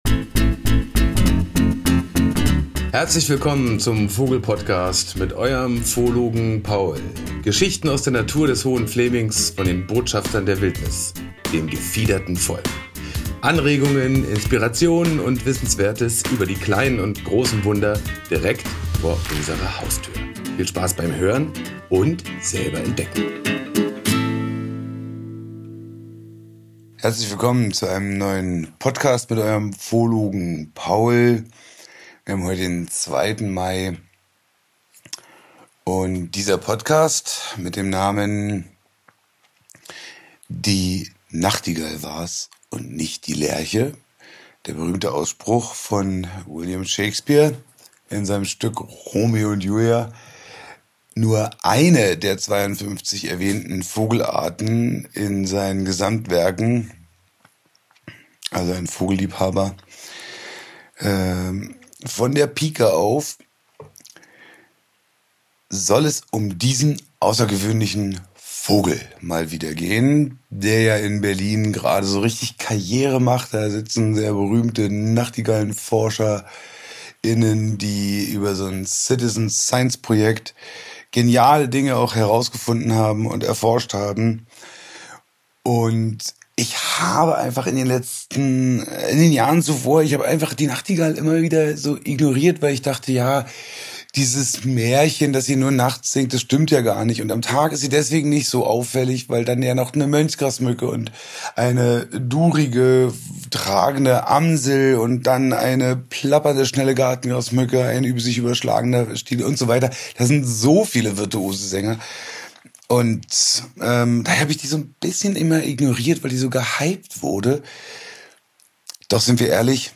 Beschreibung vor 1 Jahr (Empfehlung: Klangbildreise mit Kopfhörern genießen!) Inspiriert vom Gesang der Nachtigall, wollte ich verschiedene melodiöse Singvogelarten vorstellen.
Ich war an verschiedenen Nächten unterwegs um das akustische Spektakel aufzunehmen, zu interpretieren und die Faszination mit Euch zu teilen. Neben den Live-Aufnahmen ist diese Folge mit wissenswerten Informationen über die Nachtigall gewürzt.
Lasst euch mitnehmen in die fantastische Klangwelt der Nachtigall und ihre versteckte Botschaft an uns.